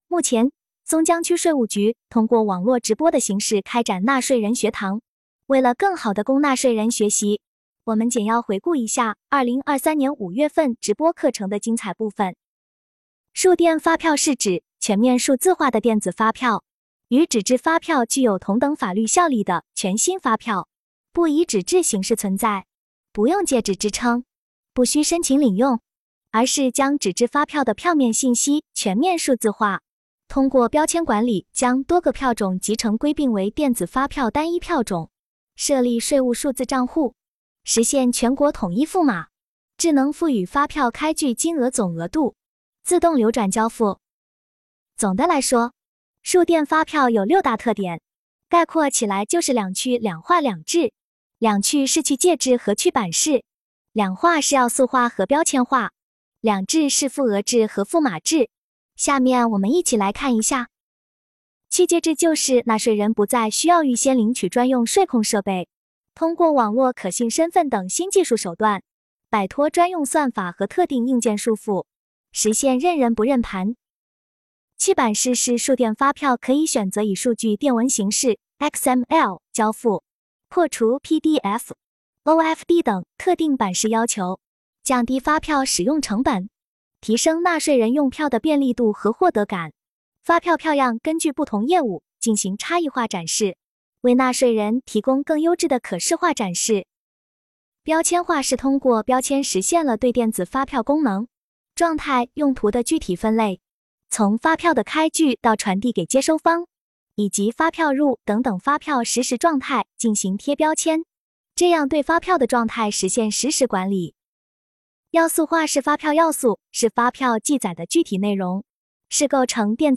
目前，松江区税务局通过网络直播的形式开展了纳税人学堂。
直播课程一